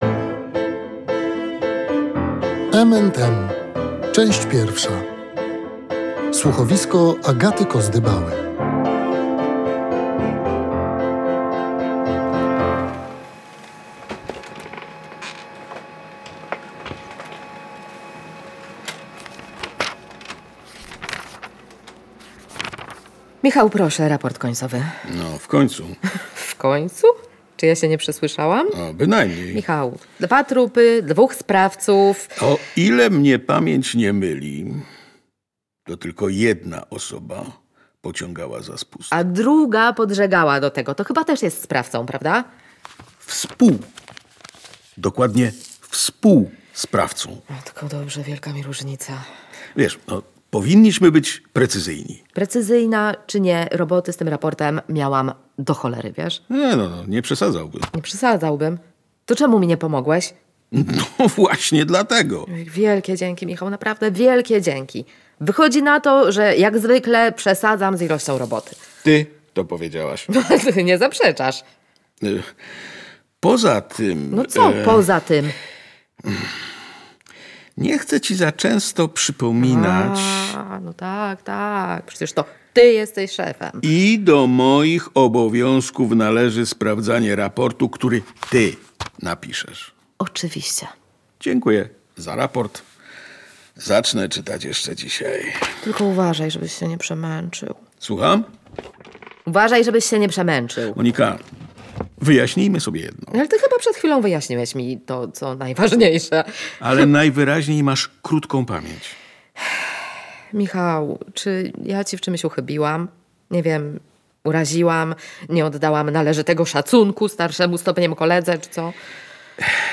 Śmierć starszego, zamożnego mężczyzny jest tylko elementem łańcucha rodzinnych tajemnic. Będą je Państwo mogli odkrywać, śledząc losy bohaterów słuchowiska „M&M”.